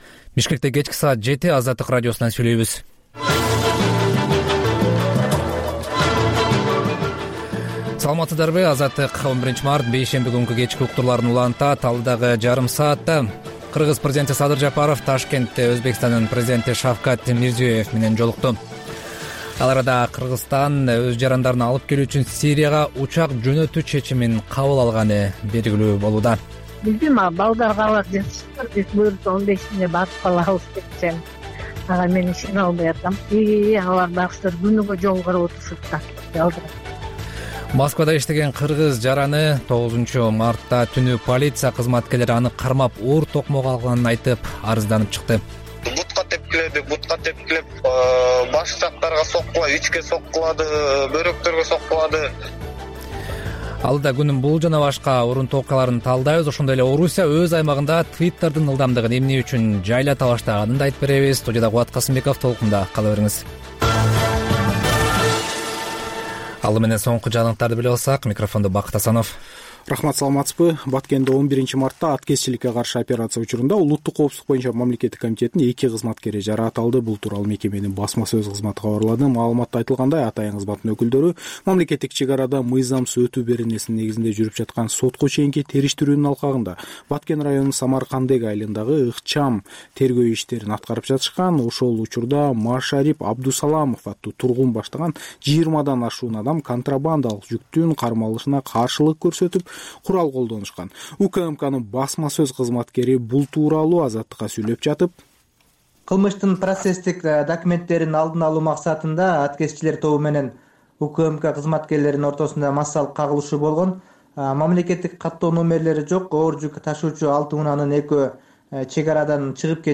Бул үналгы берүү ар күнү Бишкек убакыты боюнча саат 19:00дан 20:00га чейин обого түз чыгат.